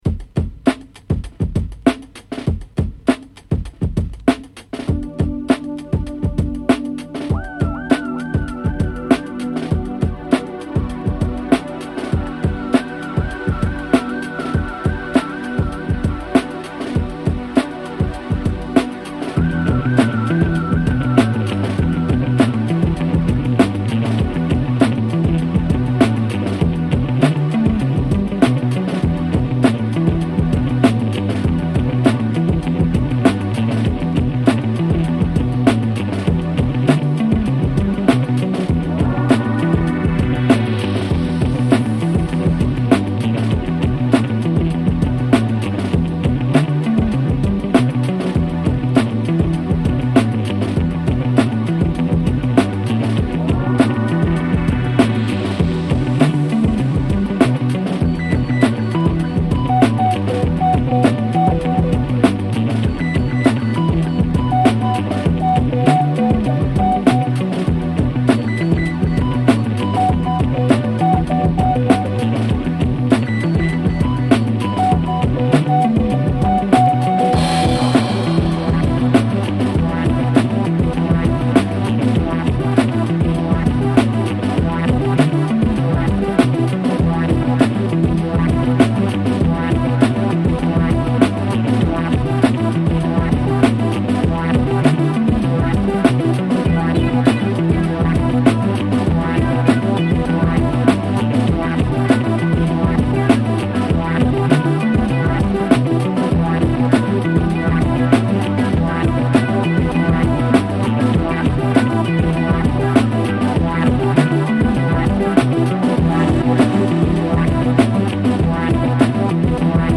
ジャズ・ファンク〜 ディスコ等あらゆる ブラックミュージックを昇華した新世代ダンスミュージック！